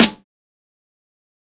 TC SNARE 02.wav